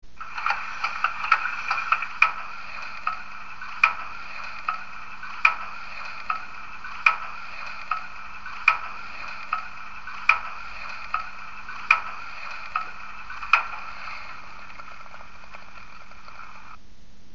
BR 18.4 mit ESU Loksound mfx Sound 4:
Sound 4 ist die Luftpumpe, jeweils mit einem Ton, als würde da irgendwo Wasser auslaufen